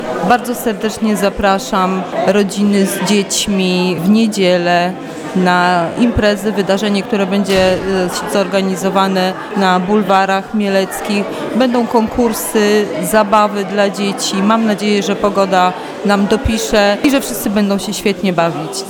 Mówi wiceprezydent miasta Adriana Miłoś.